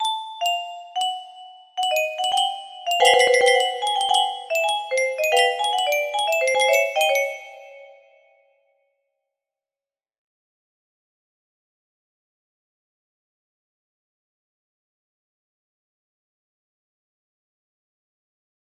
amber music box melody